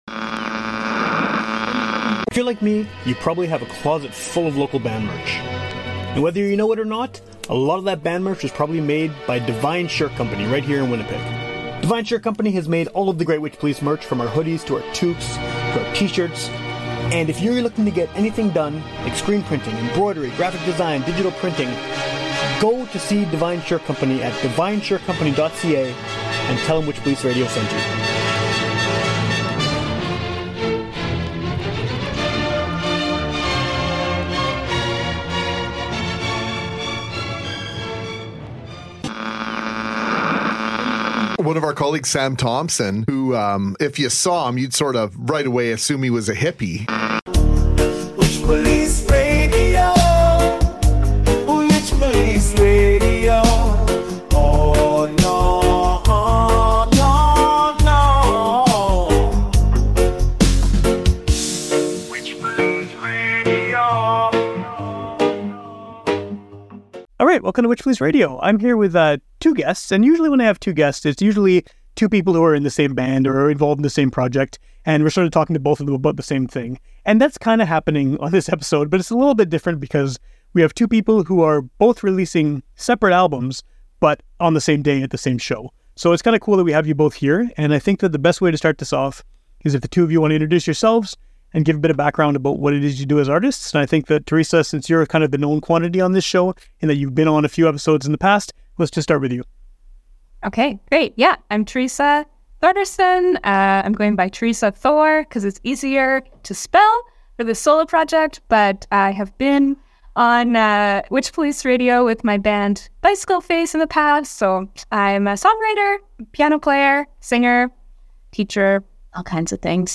Great conversation on this episode